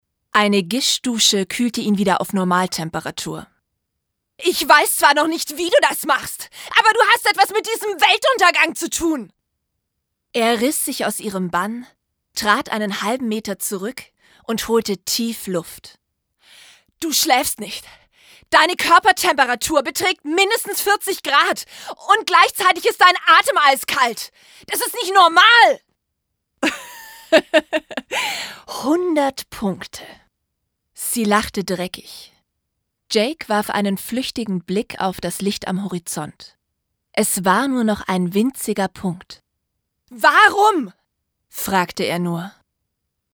Mein Equipment: Rode T-1000.
Hörbuch (verschiedene Personen): Die Flucht
2_Hoerbuch_Die_Flucht.mp3